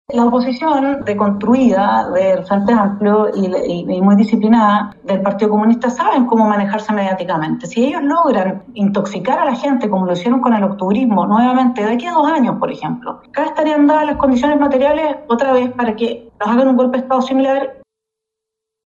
Por su parte, la senadora electa del Partido Nacional Libertario, Vanessa Kaiser, señaló a Bio Bio La Radio que esta definición requiere un análisis profundo.